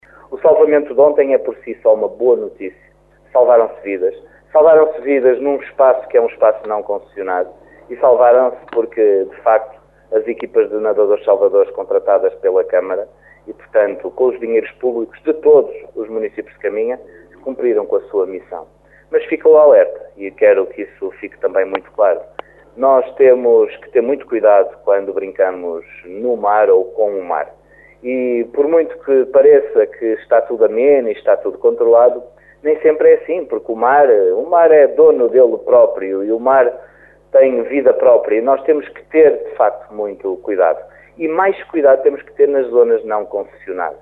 Satisfeito com a prontidão dos nadadores salvadores, Miguel Alves aproveita para deixar um alerta.